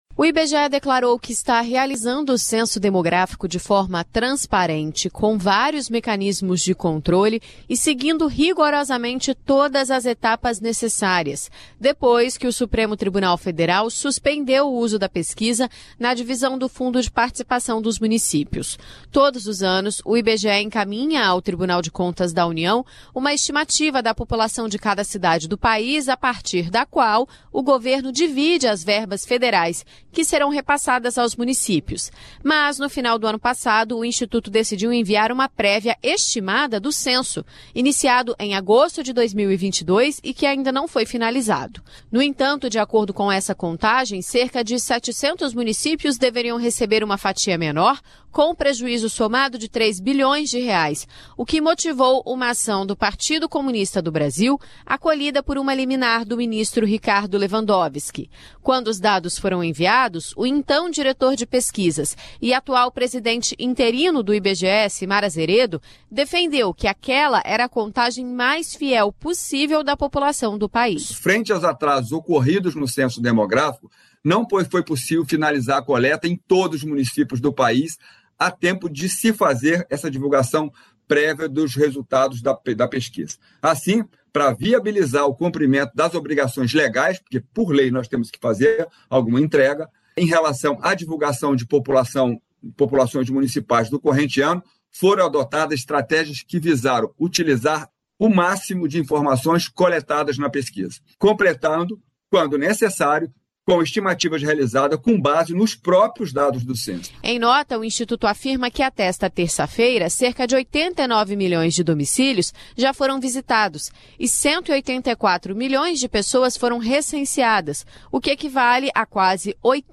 Repórter da Rádio Nacional